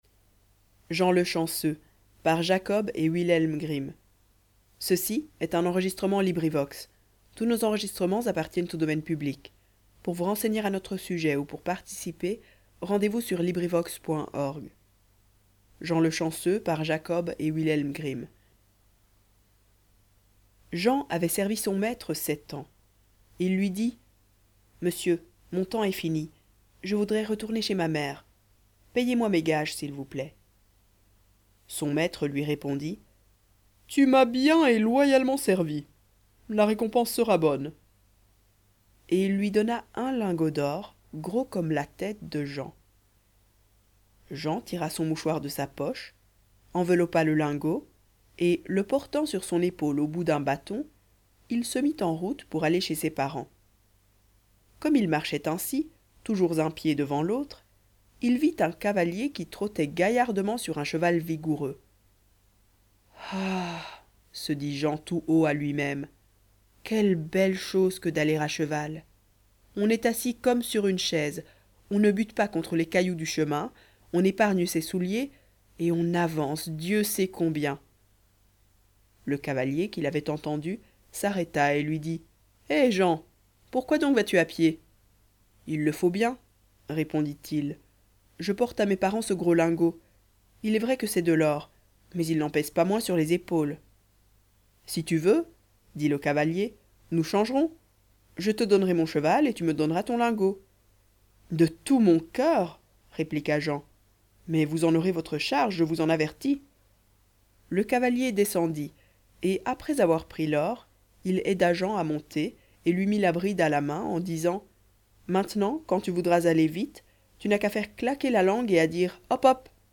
Conte